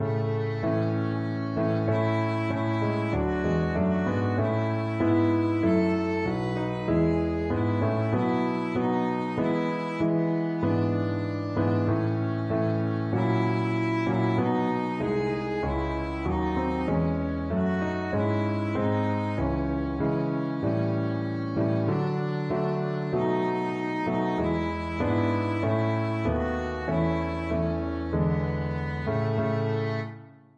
Violin
Not too slow = c. 96
4/4 (View more 4/4 Music)
A major (Sounding Pitch) (View more A major Music for Violin )
Classical (View more Classical Violin Music)